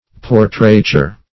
Portraiture \Por"trai*ture\, v. t.